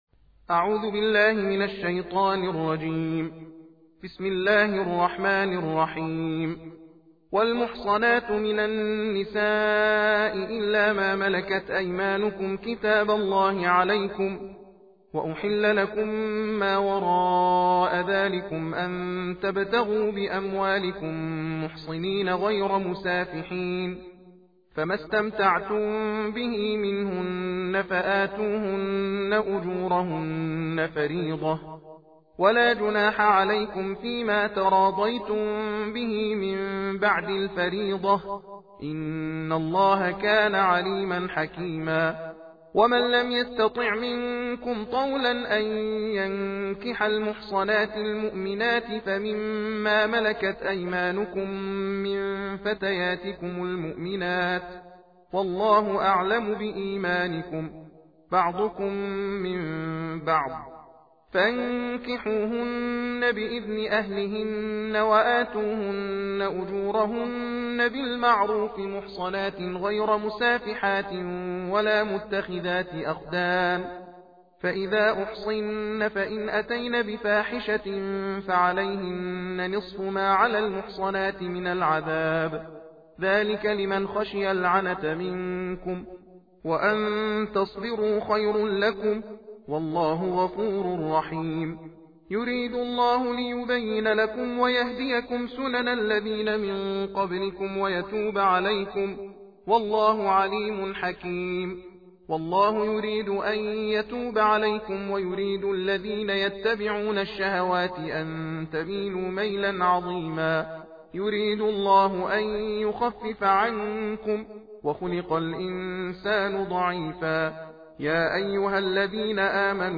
دانلود تحدیر قرآن کریم – تندخوانی جزء به جزء
تندخوانی قرآن کریم - جزء۰۵